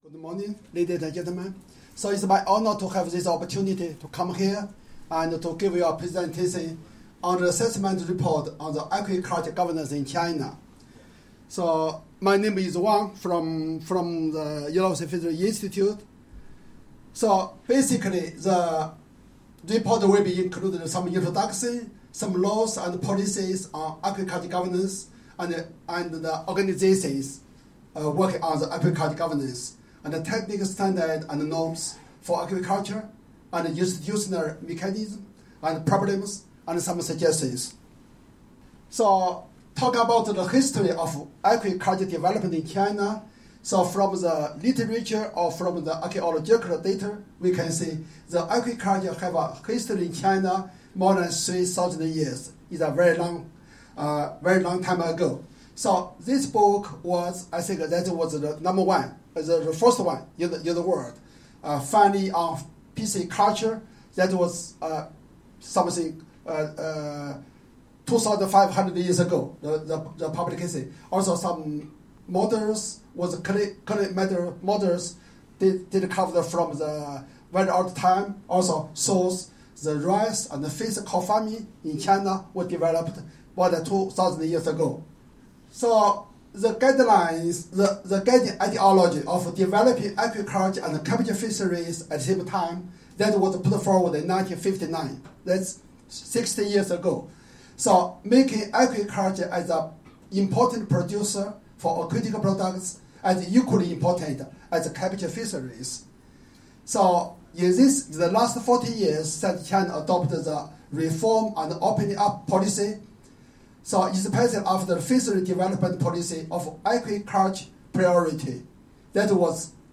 Audio recording of presentation delivered at the Consultation on Strengthening Governance of Aquaculture for Sustainable Development in Asia-Pacific, 5-6 November 2019, Bangkok, Thailand.